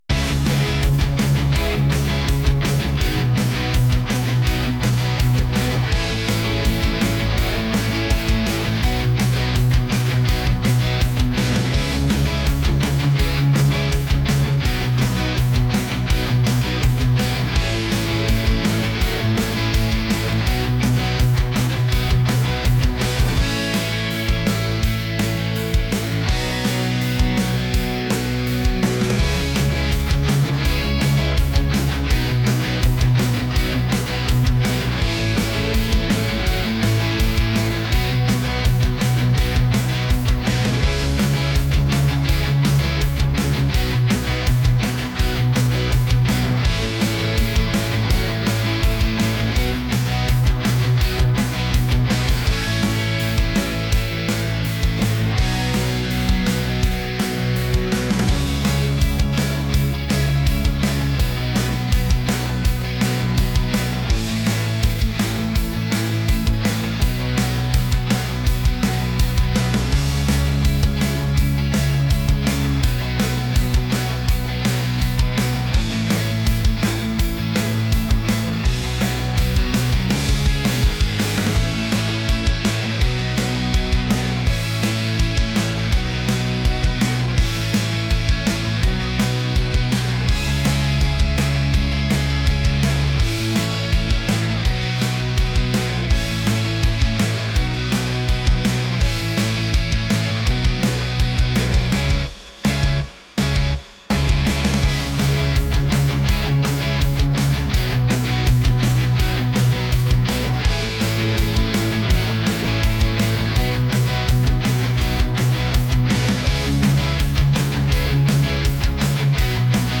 pop | energetic